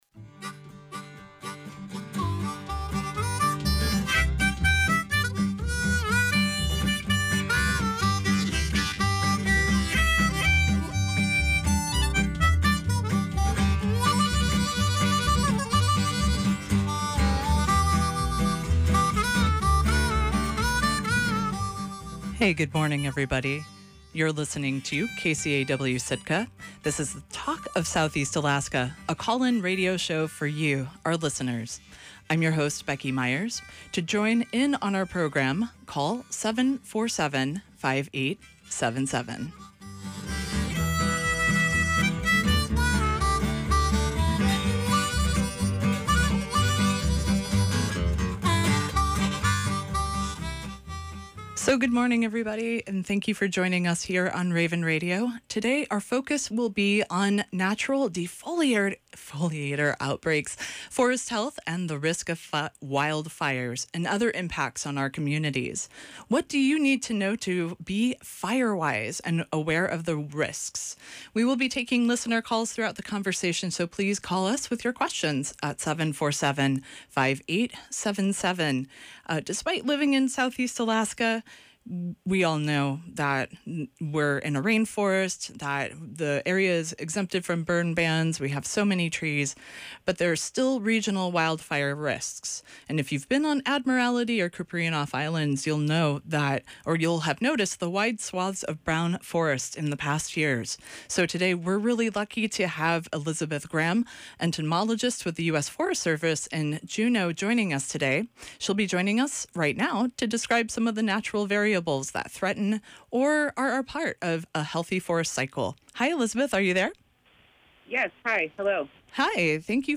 Talk of Southeast Alaska is a call-in radio program about regional issues faced by folks in all our listening communities. In this episode, we explored issues of forest health, fire safety awareness, and resources for people living in the Tongass National Forest.